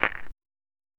Stone on stone quiet scratching sound effect how it would sound in the deep water, no high freq !
stone-on-stone-quiet-scra-jkhdihwf.wav